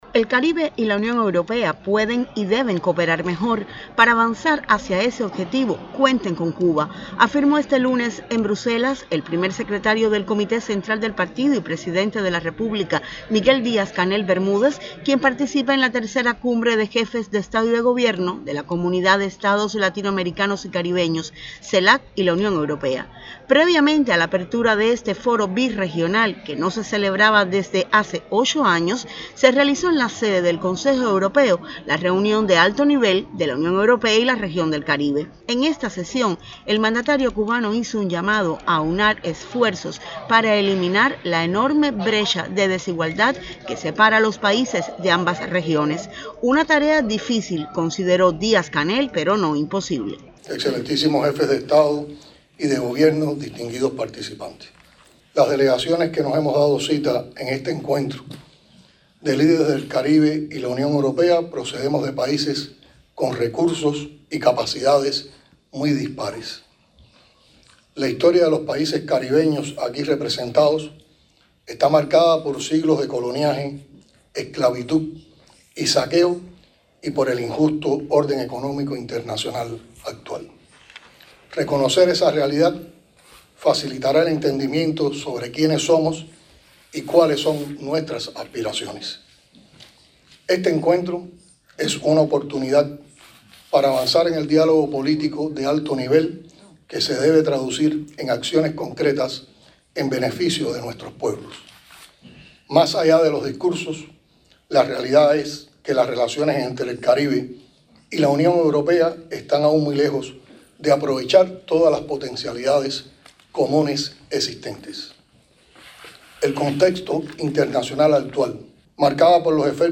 Intervención de Miguel Mario Díaz-Canel Bermúdez, Primer Secretario del Comité Central del Partido Comunista de Cuba y Presidente de la República, en el Encuentro de líderes de la Unión Europea–Caribe, previo a la III Cumbre CELAC-UE en Bruselas, Bélgica, el 17 de Julio de 2023, “Año 65 de la Revolución”.